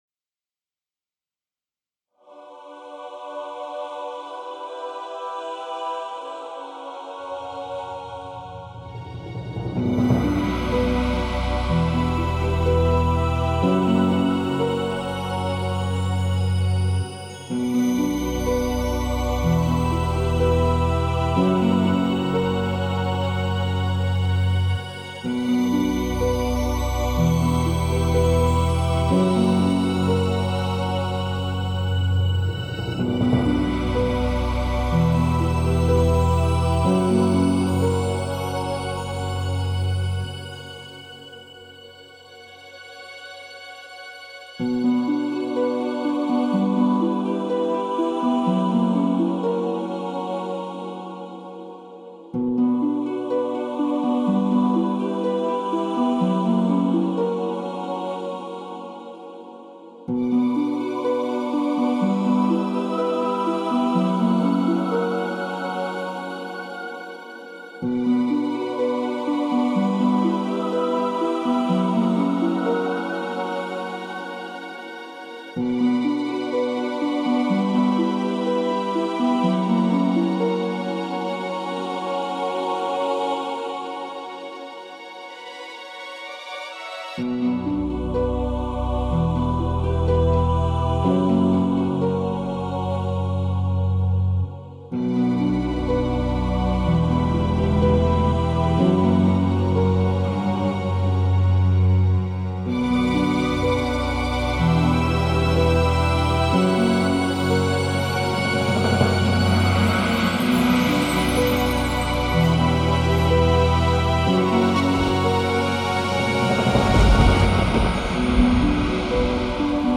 Bollywood Mp3 Music 2015